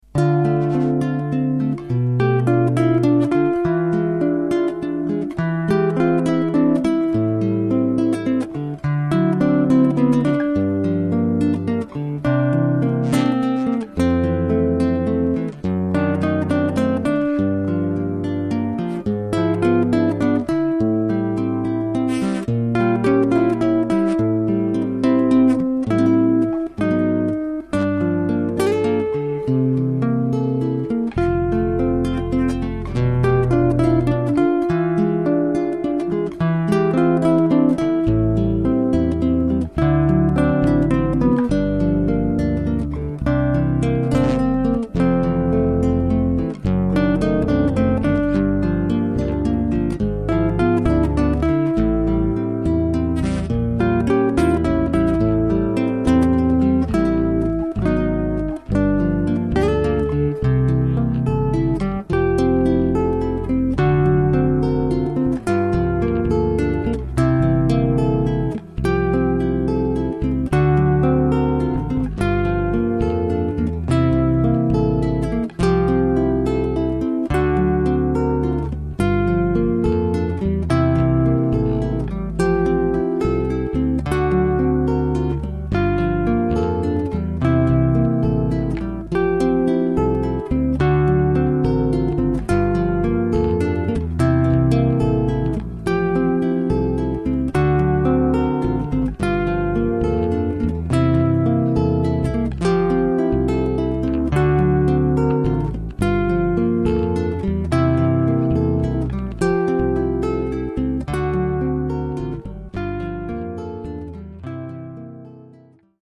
• Жанр: Авторская песня
инструменталка